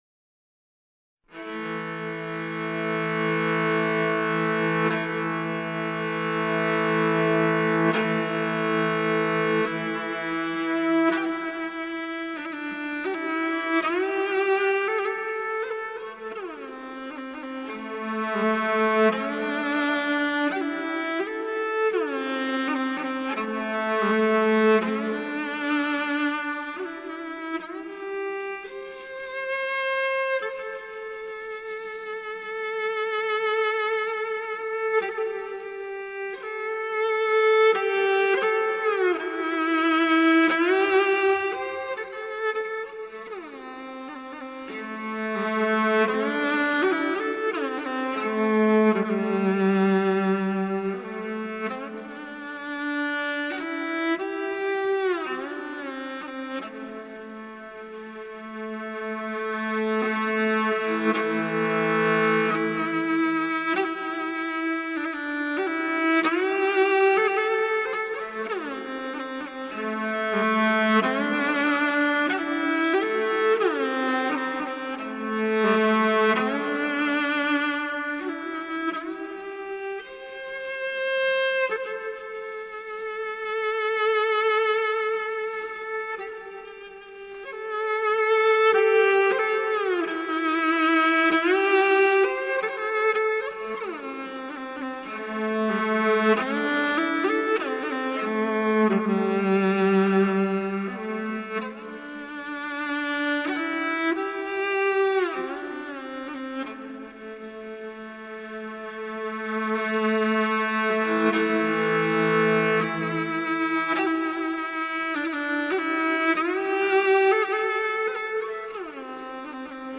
马头琴独奏